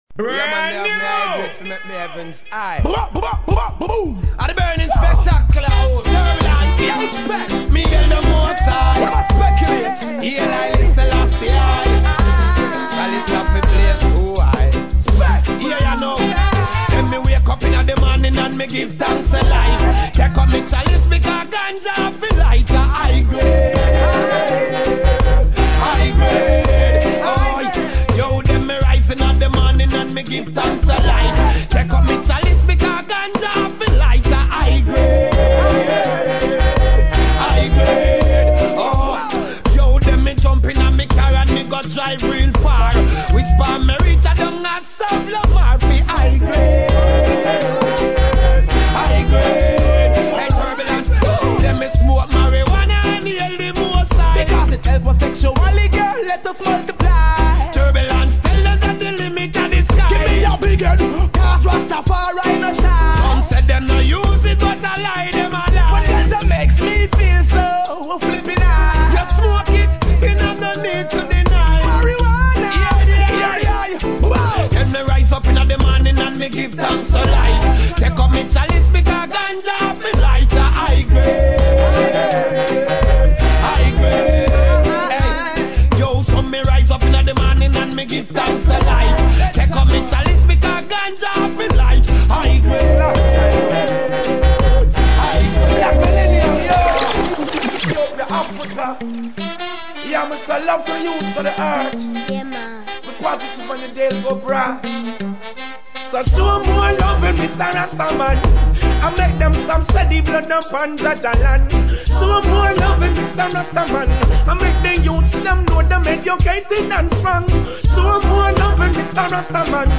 aux cuivres